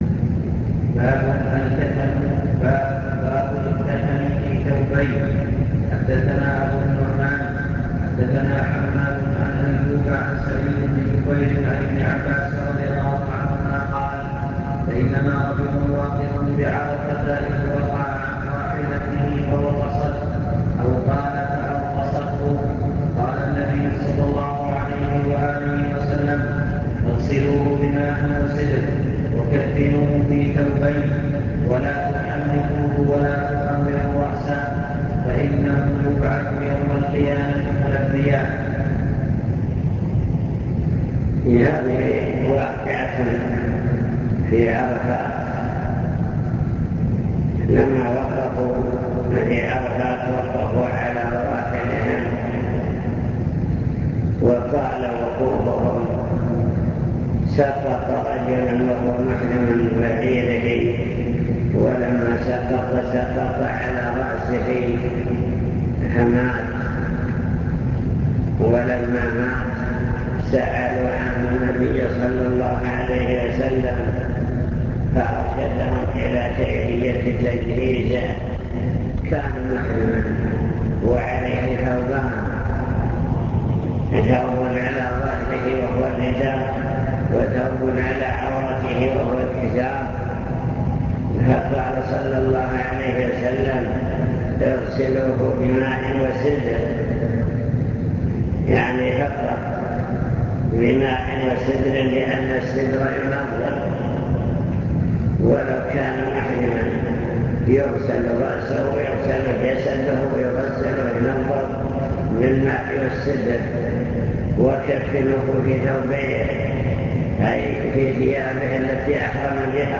المكتبة الصوتية  تسجيلات - محاضرات ودروس  محاضرة في الزلفى مع شرح لأبواب من كتاب الجنائز في صحيح البخاري